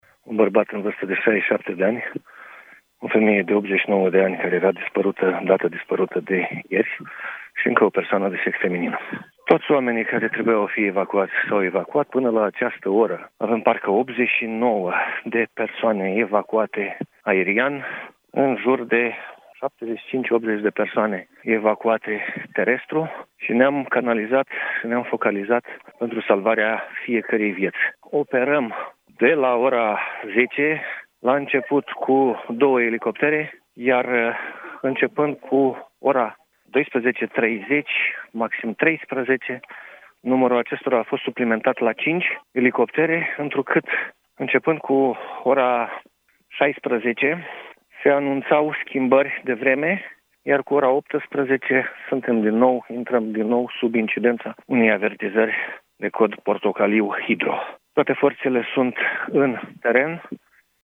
Prefectul Sucevei, Traian Andronachi: Au fost descoperite trei victime